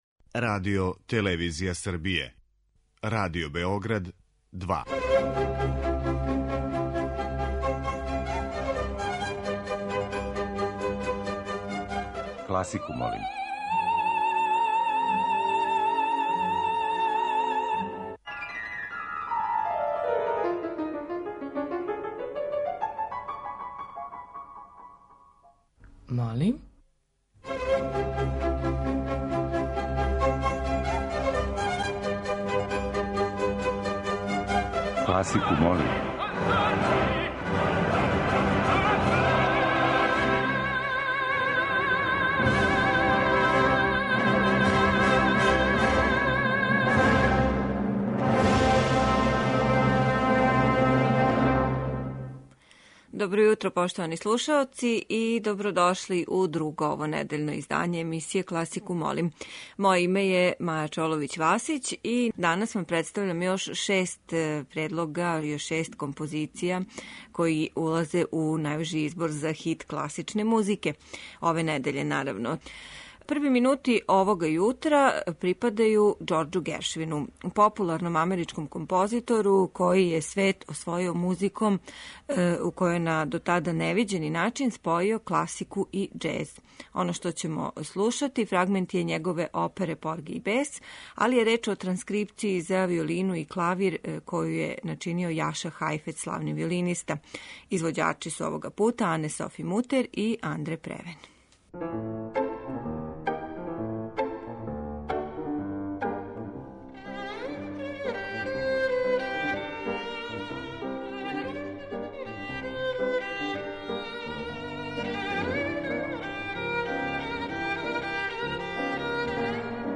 Избор за недељну топ-листу класичне музике Радио Београда 2
преузми : 20.70 MB Класику молим Autor: Група аутора Стилски и жанровски разноврсни циклус намењен и широком кругу слушалаца који од понедељка до четвртка гласају за топ листу недеље.